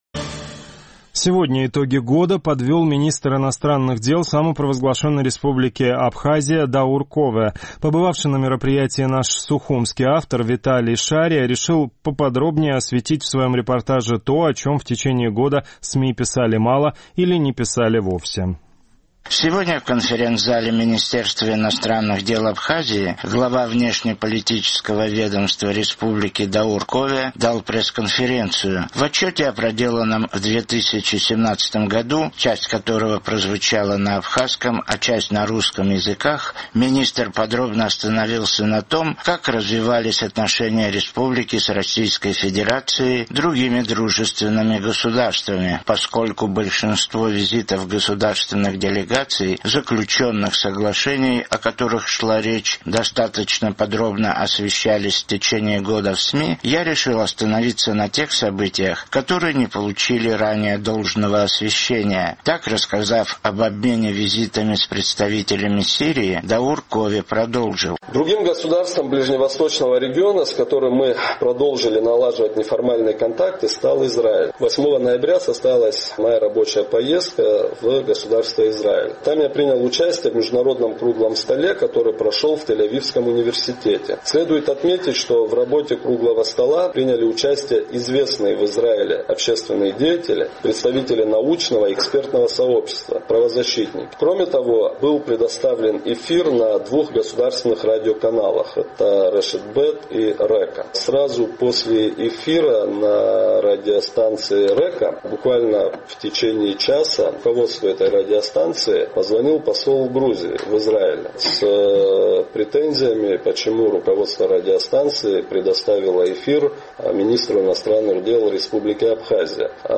Сегодня в конференц-зале Министерства иностранных дел Абхазии глава внешнеполитического ведомства республики Даур Кове дал пресс-конференцию.